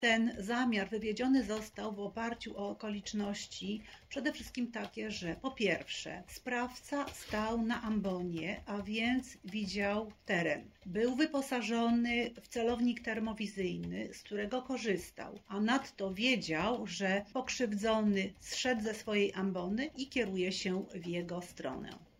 mówi prokurator